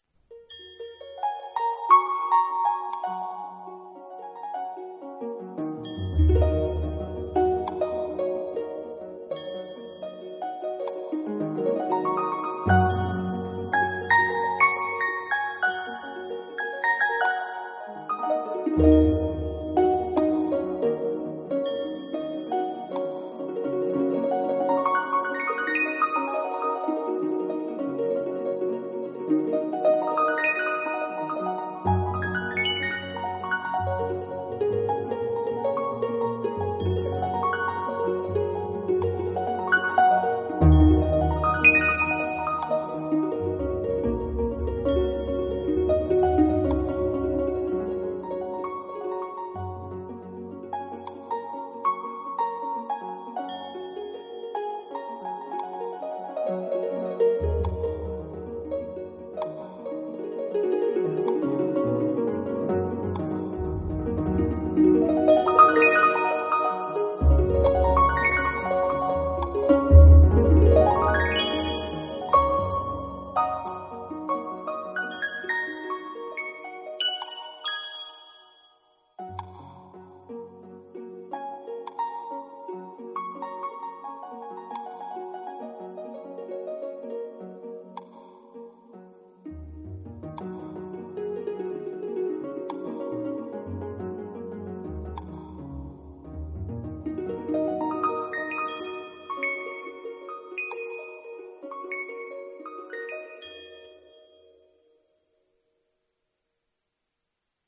Instrumental Hymn - Audio